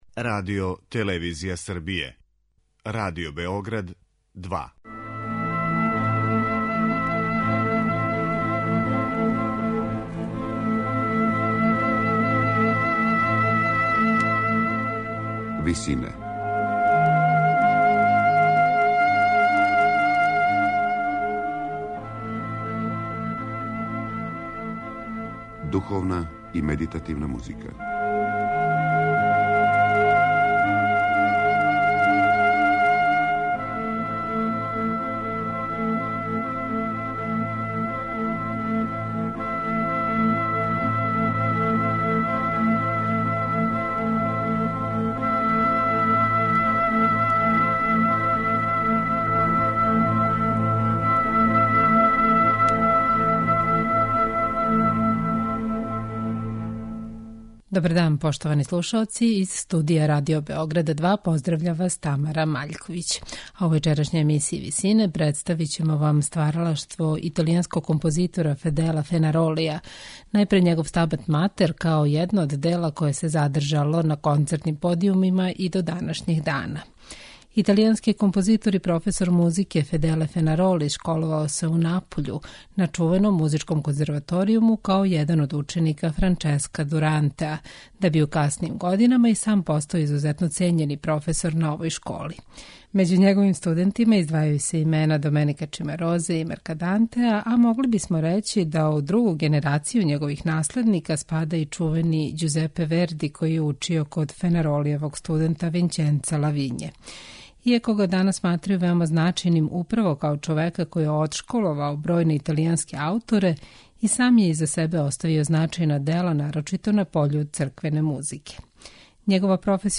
Иза себе је оставио и значајна дела, нарочито на пољу црквене музике. Међу њима је и Стабат матер, дело настало 1775. године за извођачки састав сачињен од мушког сопрана, контратенора, гудача и континуа.